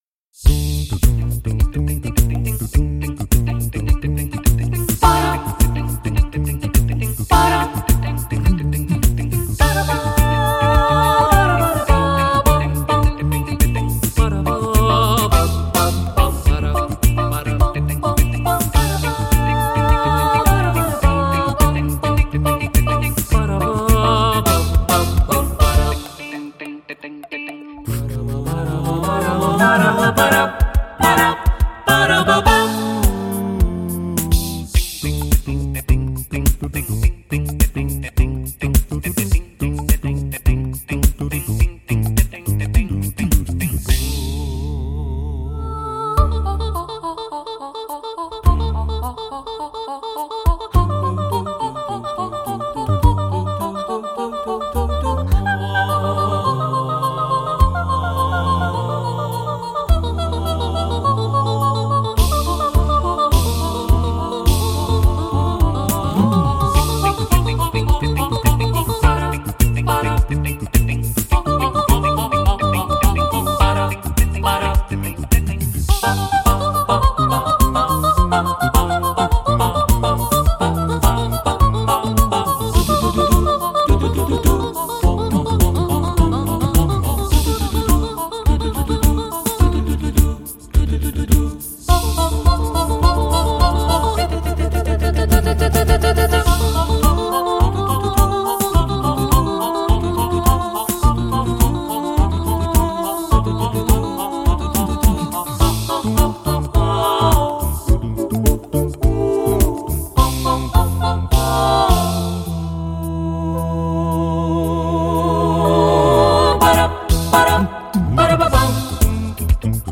۴. آواز آکاپلا (A cappella)
اجرایی کاملاً بدون همراهی ساز، که تمامی عناصر موسیقایی شامل ملودی، هارمونی، ریتم و حتی شبیه‌سازی‌های بیس و درام توسط صدای انسان تولید می‌شود.